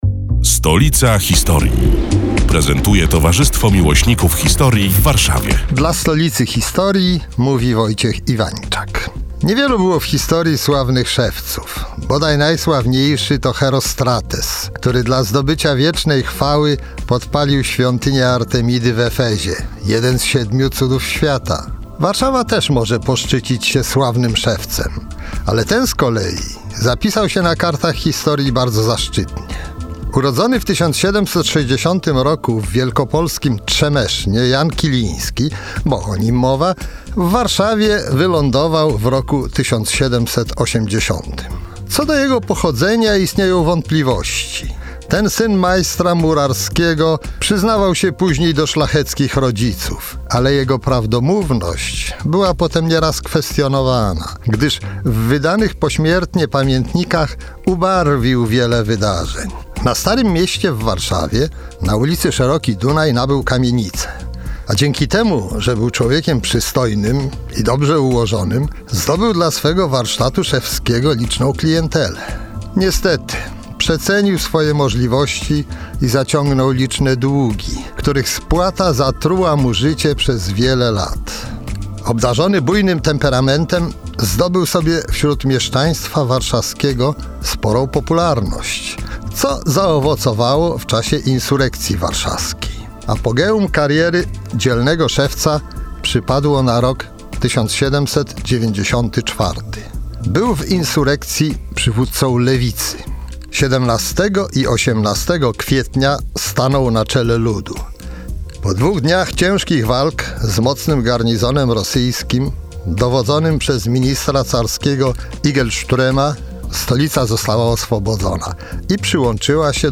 Podcast Radia Kolor jest pod tekstem.